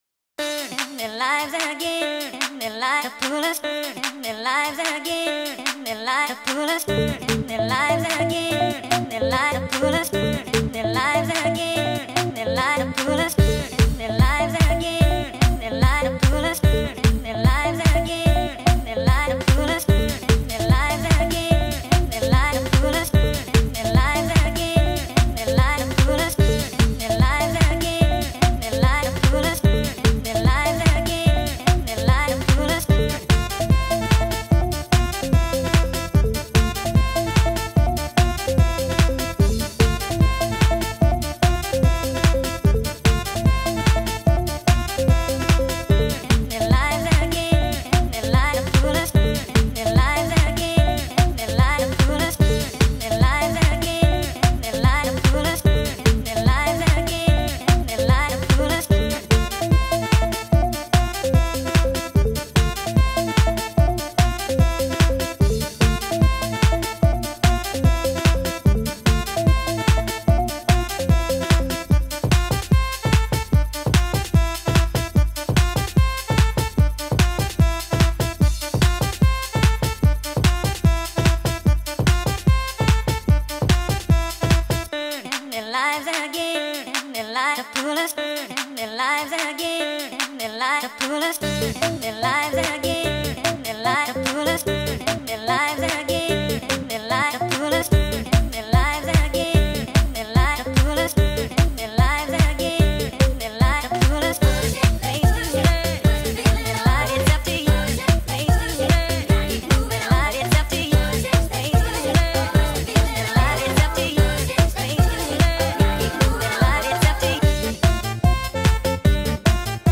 فانک
شاد